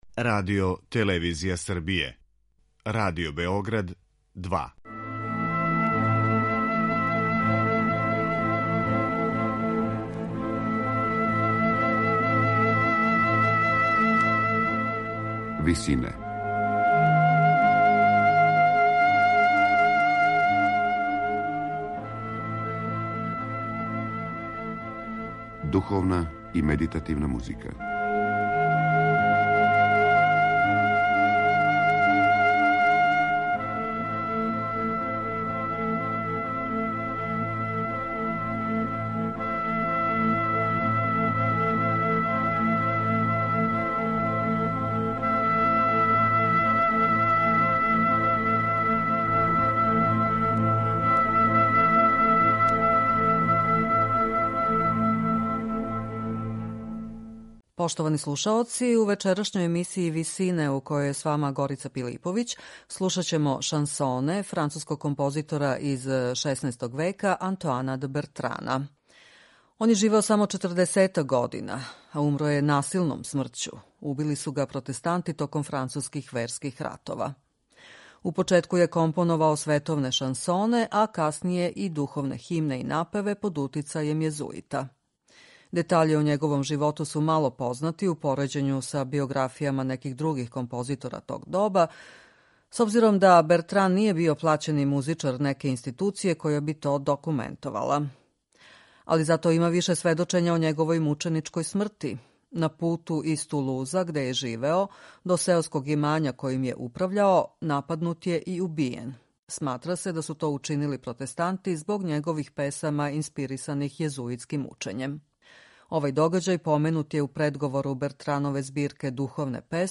Ренесансне шансоне